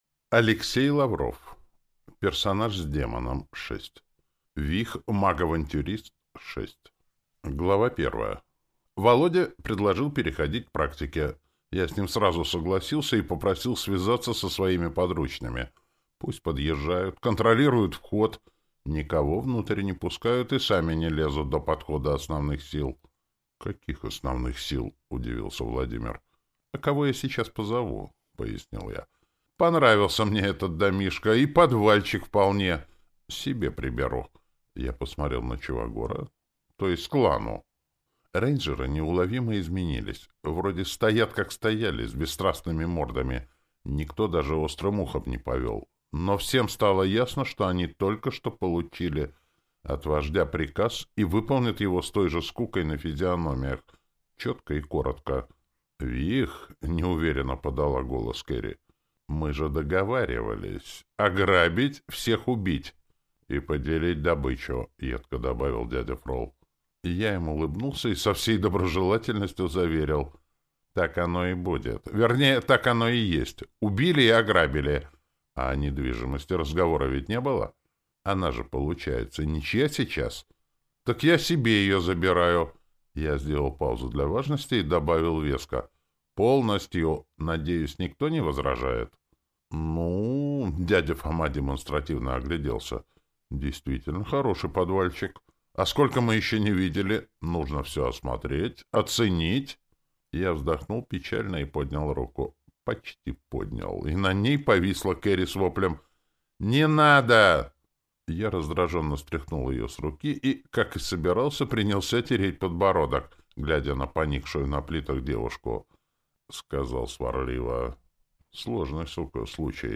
Аудиокнига Персонаж с демоном 6 | Библиотека аудиокниг
Прослушать и бесплатно скачать фрагмент аудиокниги